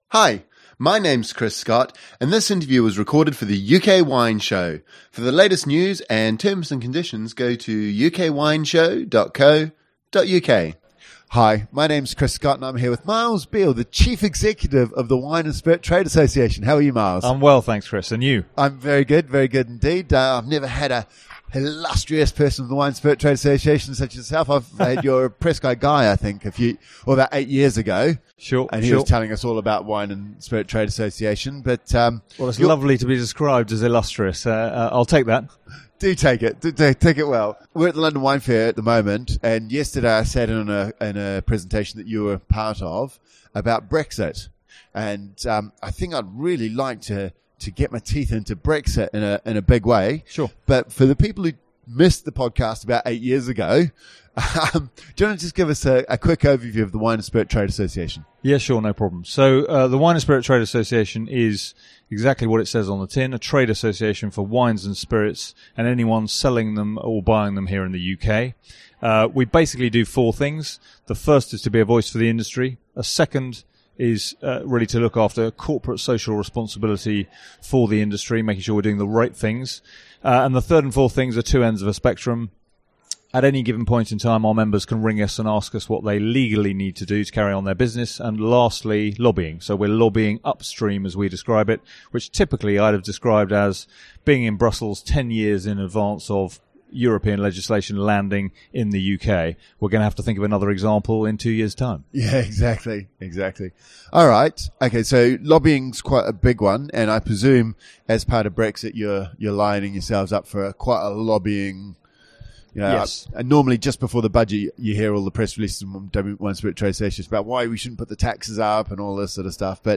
We caught up with him at the London Wine Fair to discuss the potential impact of Brexit on the wine industry. We talk about the current tariffs in Europe for wine and what it would mean for the UK should we take the 'No deal is better than a bad deal' approach and trade under WTO rules. We also look at some of the opportunities that may exist under Brexit.